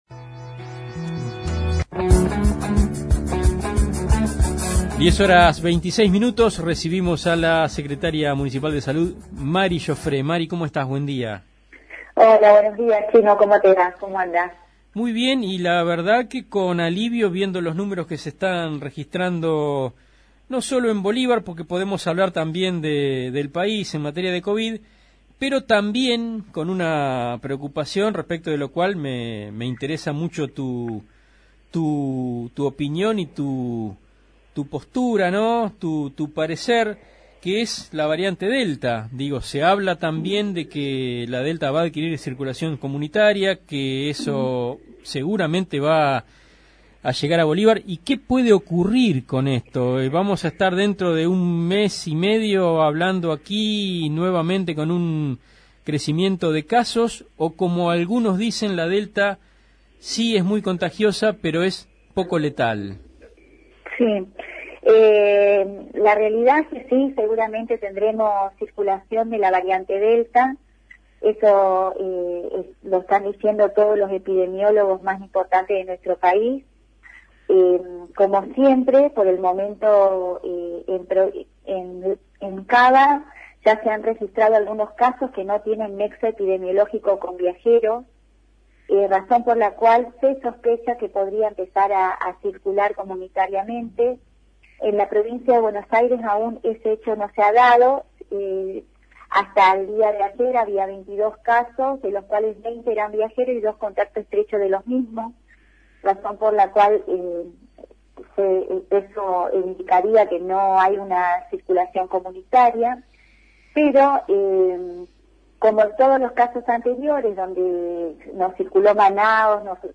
Mary Jofré, Secretaria de Salud